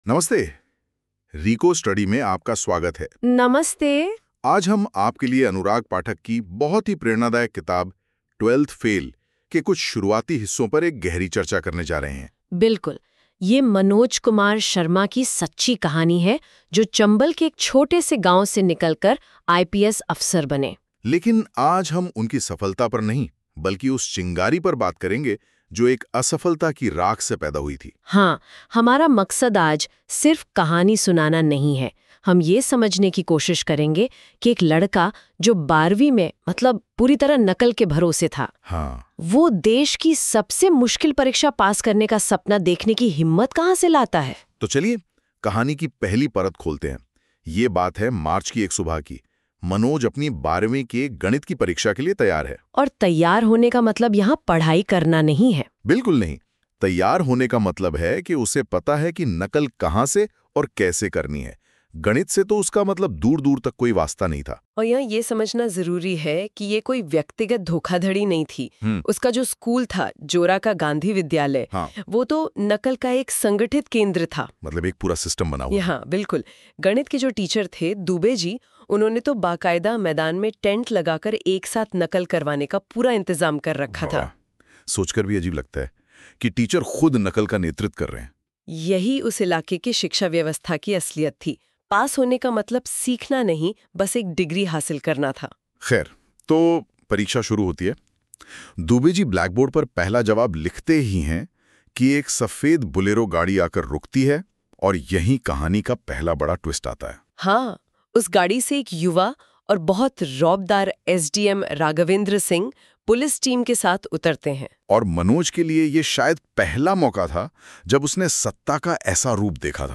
12th-fail-अनुराग-पाठक-Audio-Book.mp3